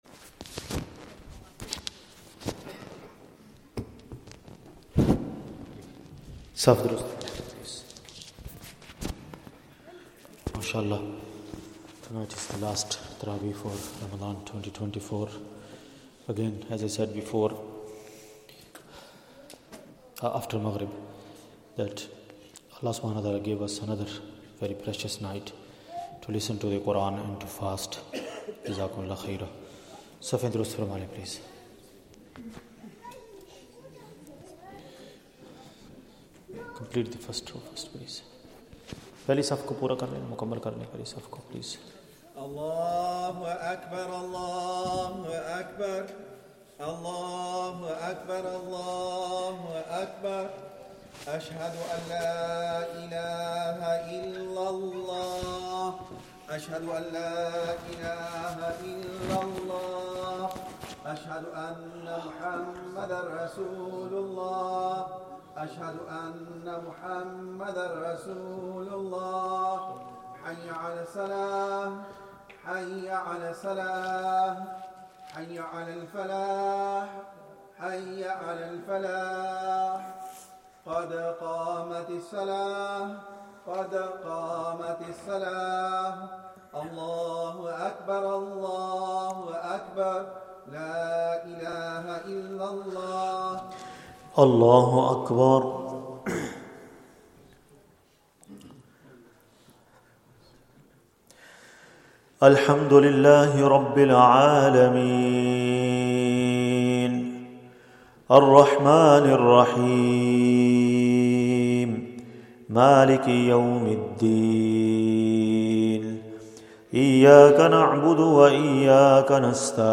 Taraweeh 30th night of Ramadhan.